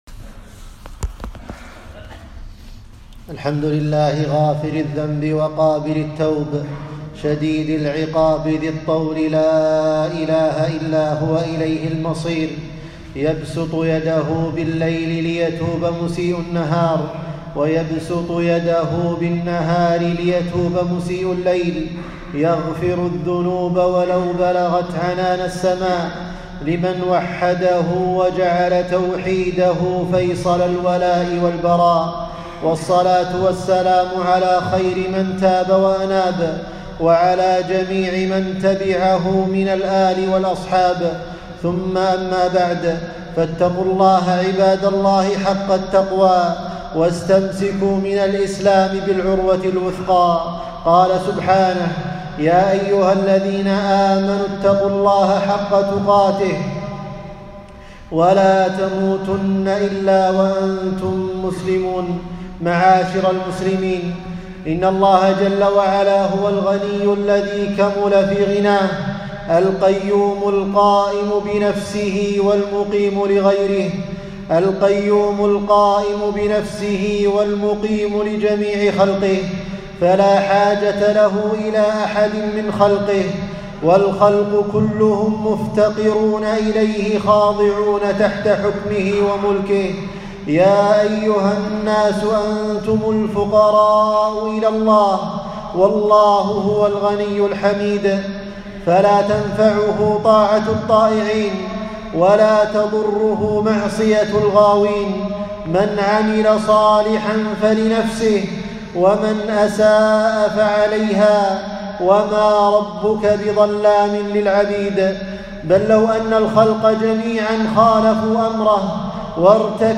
خطبة - التوبة إلى الله